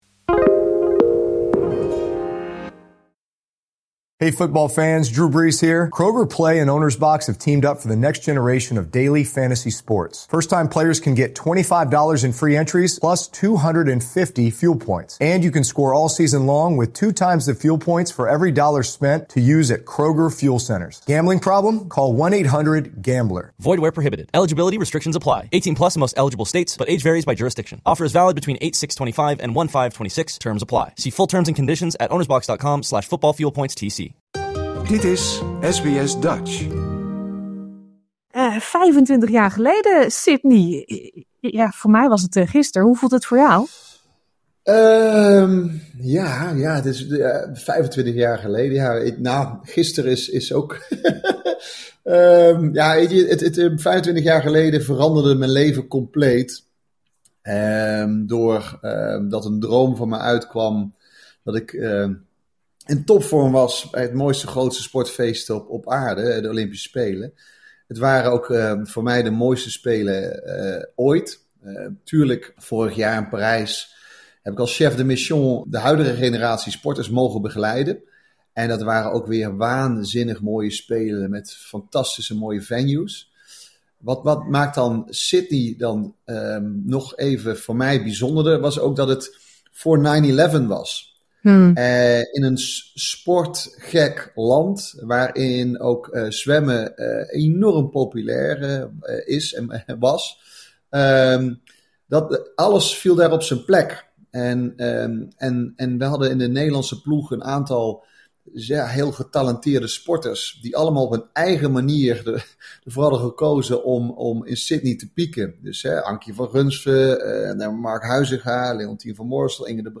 'Hoogie' vertelt in een uitgebreid interview onder meer over zijn voorbereiding, dé race tegen Thorpe en de warmte van de Australische fans.